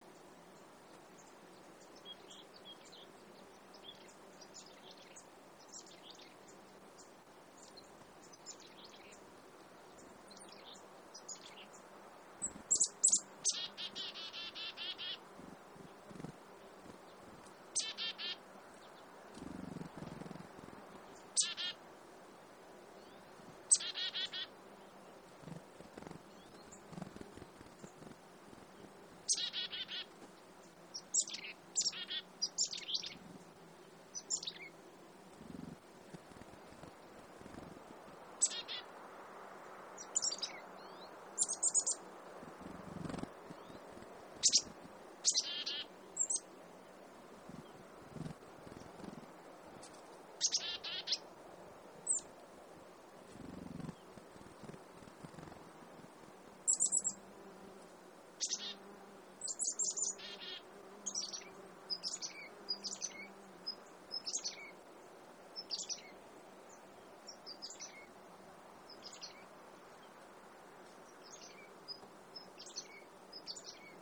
An energetic auditory scene framed around a pair of Black-capped Chickadee. Diverse vocalizations and wingbeats are audible. Distant waves can be heard along the coast near Swikshak Lagoon, Katmai National Park.
Alaskan Natural Sound Showcase